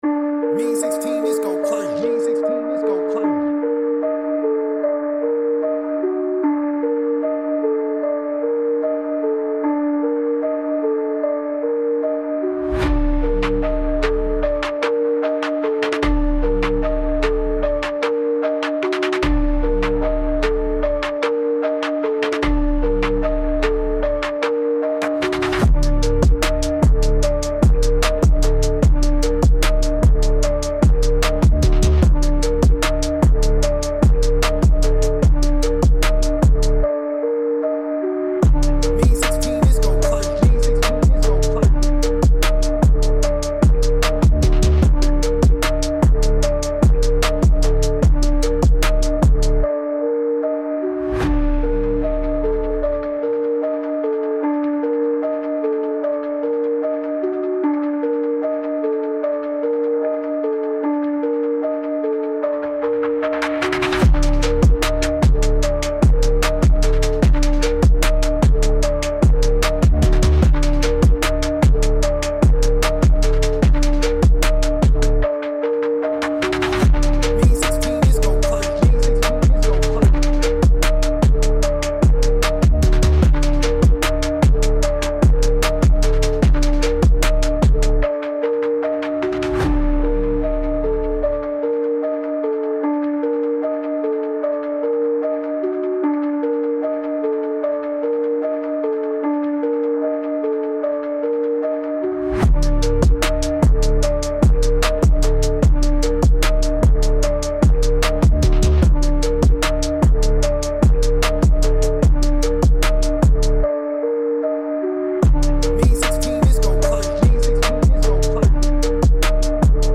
HARD TRAP BEAT
D-Min 150-BPM